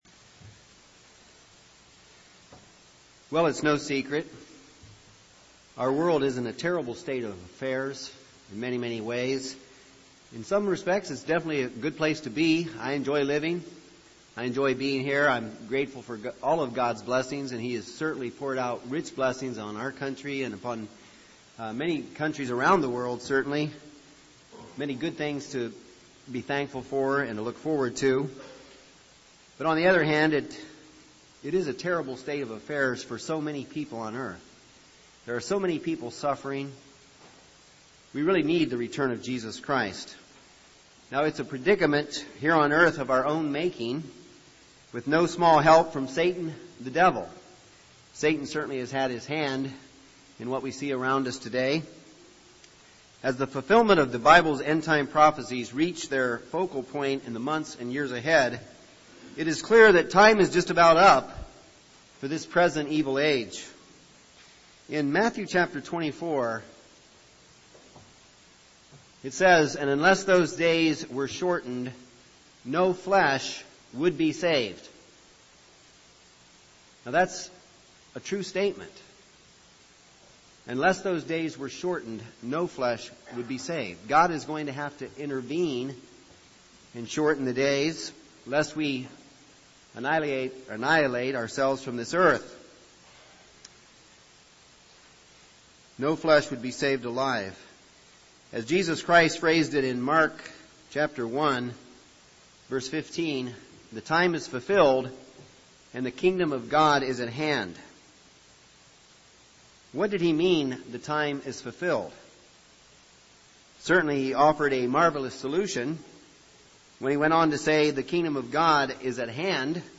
Kingdom of God Bible Seminar Series, Part 1, Session 2 Just what is the Gospel of the Kingdom of God? Christ included praying “thy Kingdom come” in the sample prayer for His disciples, but so many do not understand the truth about the Good News of the Kingdom of God.